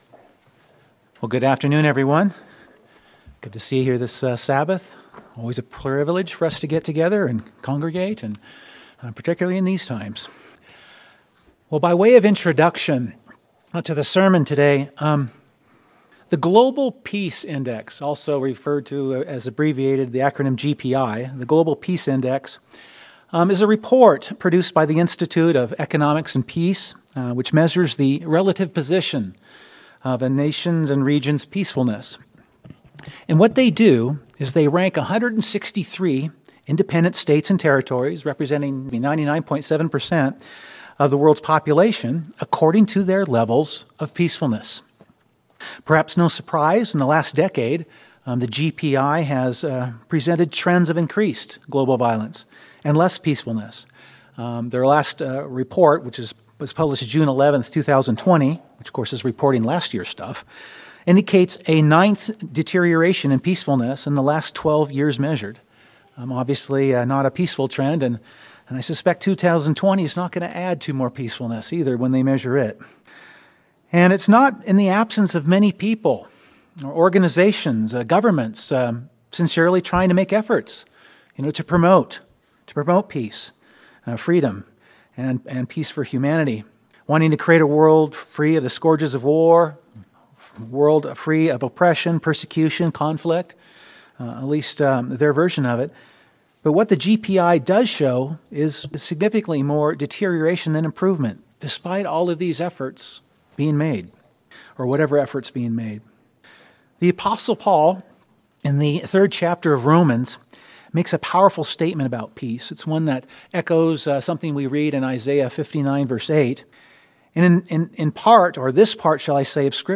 This sermon focuses on the concept of peace in the bible, what kind of peace God can give, how to keep it and grow it.